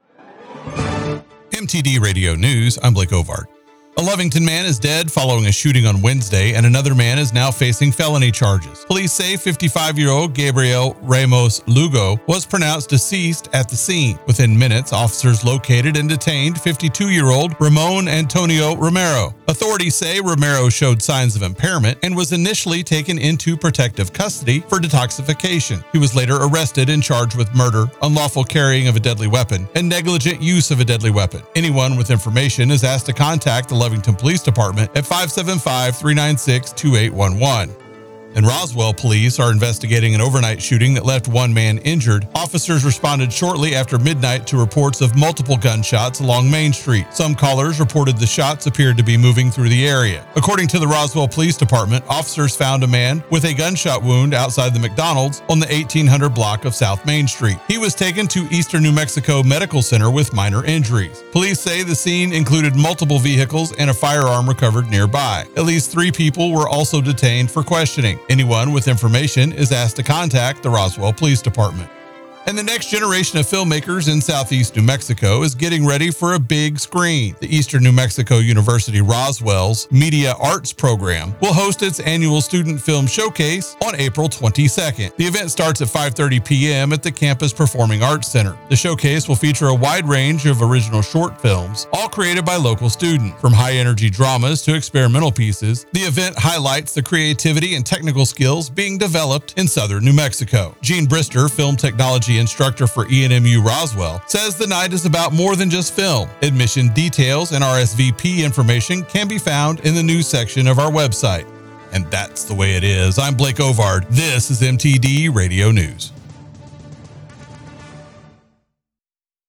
107.1 The Blaze News – New Mexico and West Texas